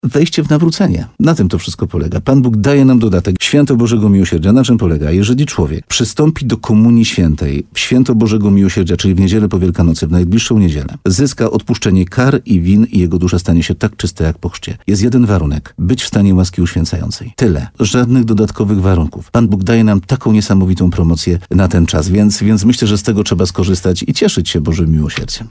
– To Bóg ma zawsze ostatnie zdanie i w czasach kłamstwa Jego słowo powinno nas obowiązywać i dawać nawrócenie – powiedział duchowny w radiu RDN Nowy Sącz.